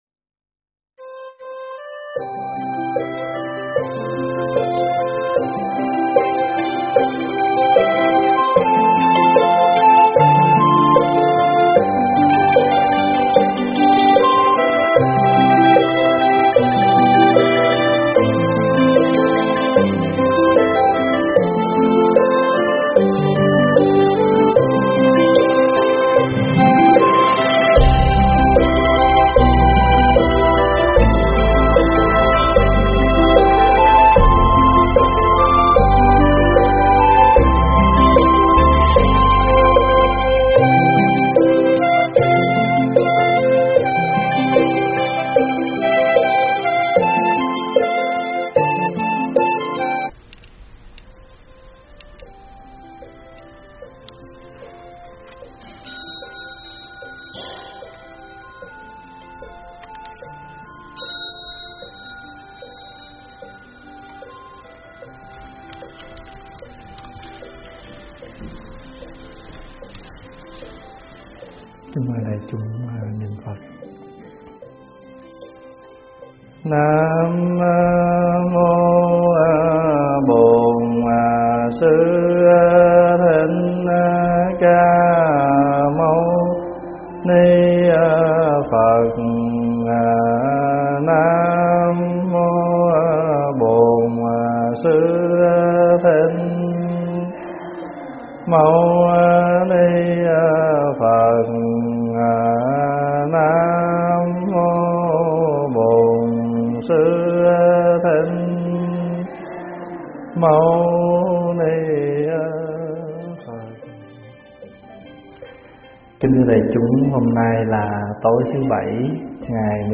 thuyết pháp Xe Báu Đại Thừa 4
giảng tại Tv. Trúc Lâm